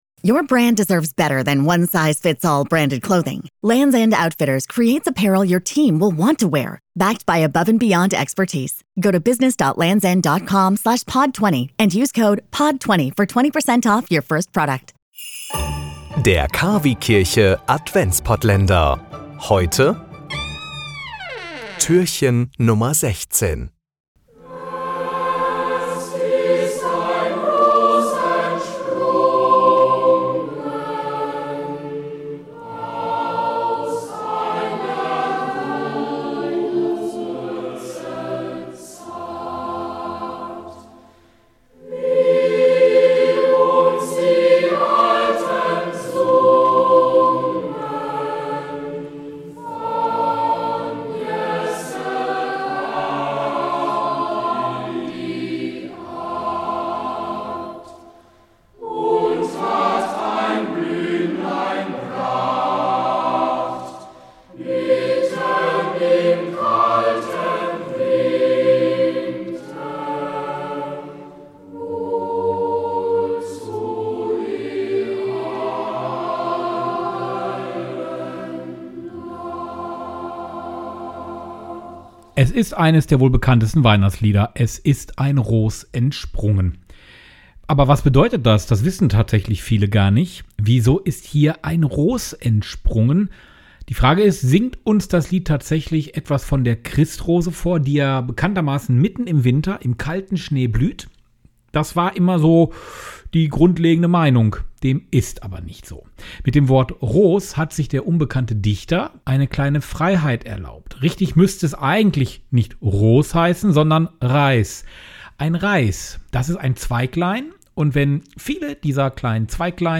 und Gedanken - in einfacher Sprache.